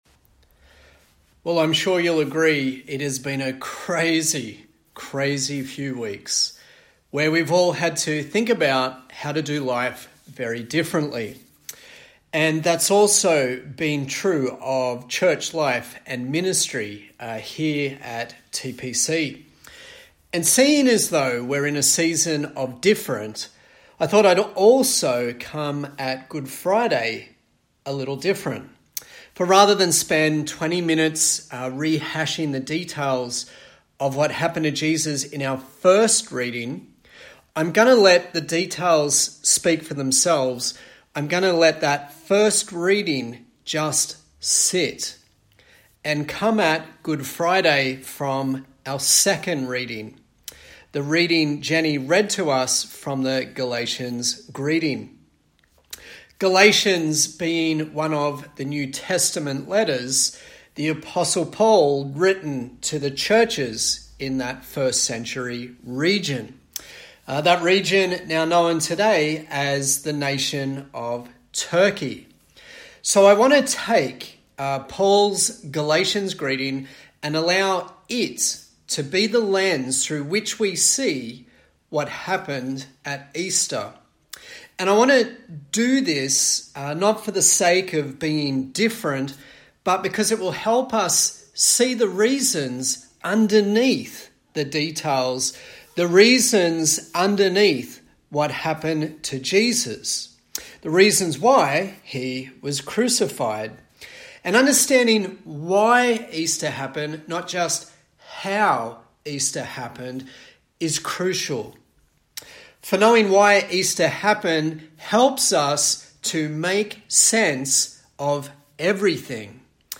Galatians Passage: Galatians 1:3-5 Service Type: Good Friday A sermon on Easter